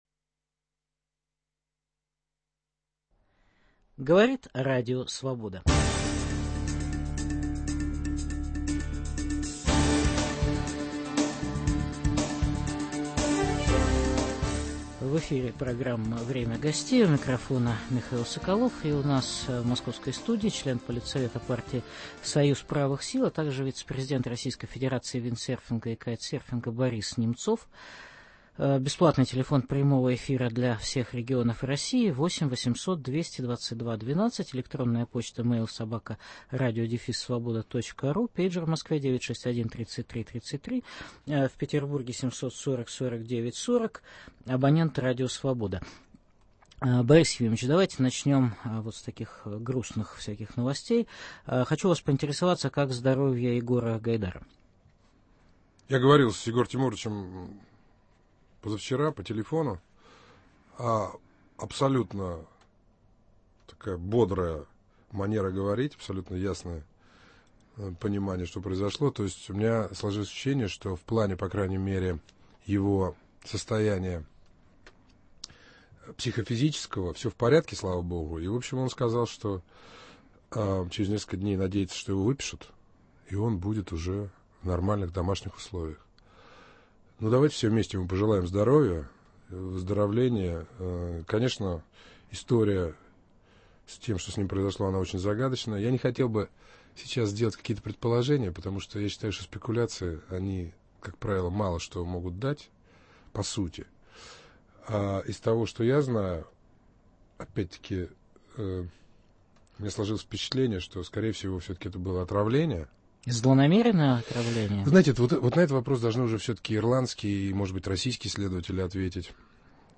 В программе выступит член политсовета партии Союз правых сил, вице-президент Российской федерации виндсерфинга и кайтсерфинга Борис Немцов.